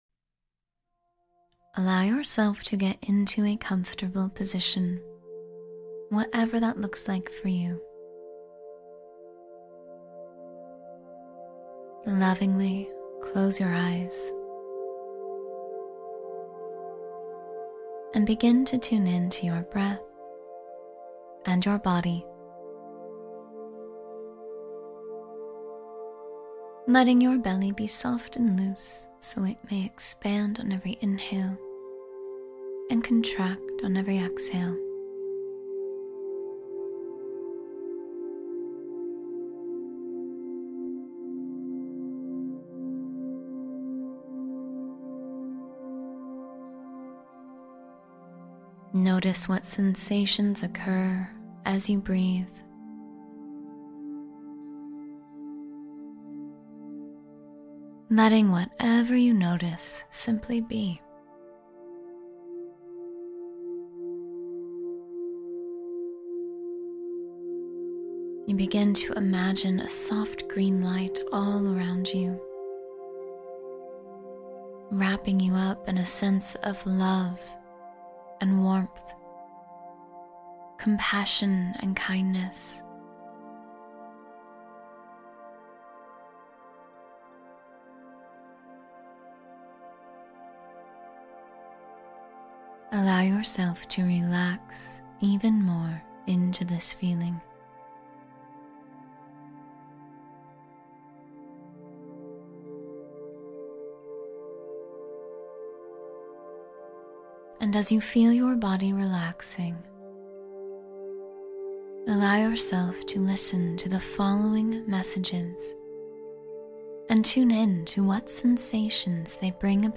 Guided mindfulness exercises